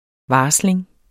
Udtale [ ˈvɑːsleŋ ]